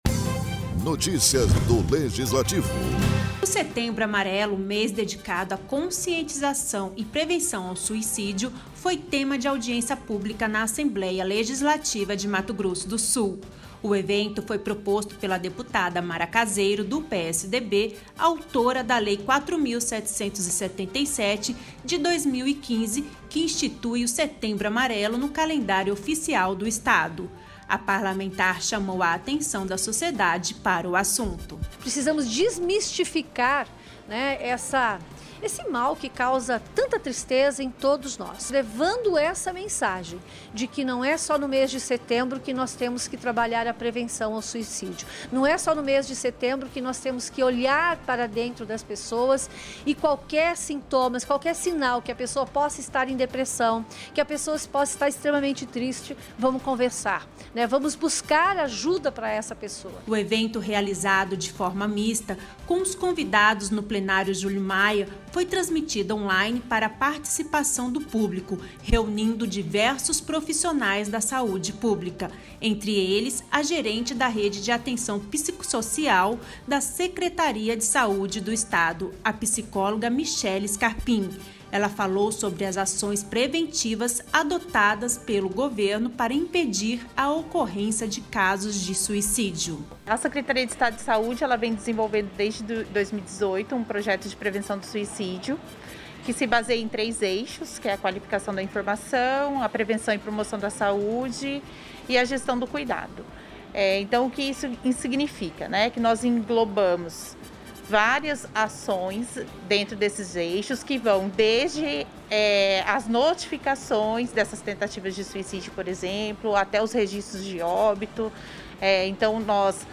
O setembro amarelo, mês dedicado a conscientização e prevenção ao suicídio foi tema de audiência pública na ALEMS.